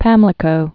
(pămlĭ-kō)